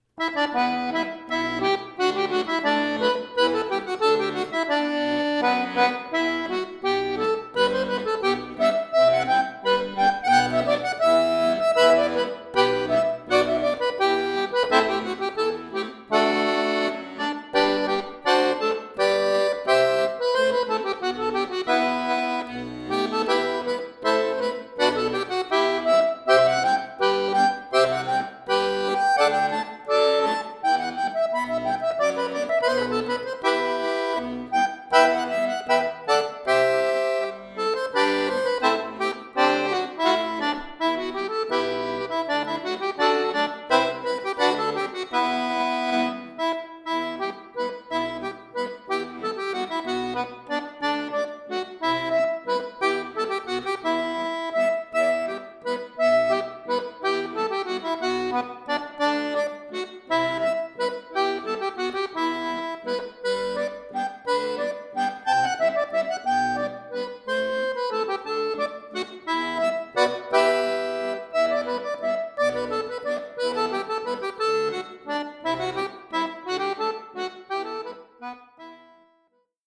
Irisch , Folk